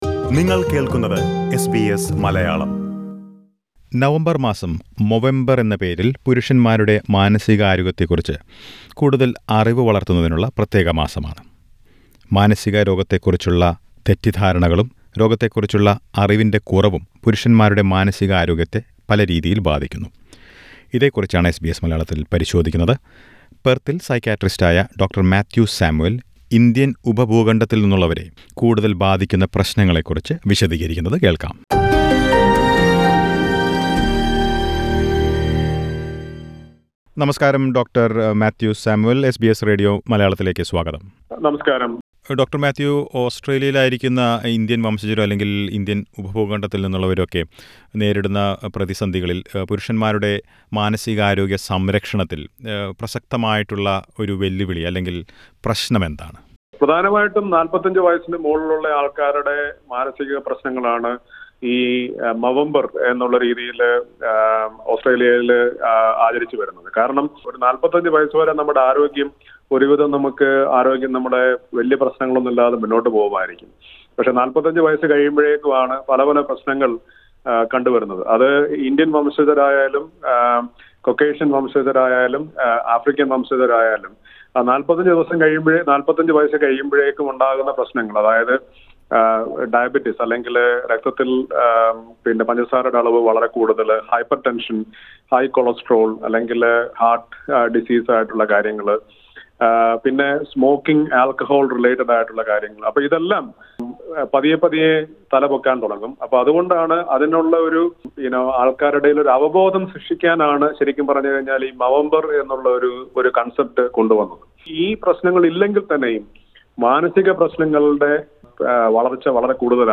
Disclaimer: The information provided in this interview is general in nature.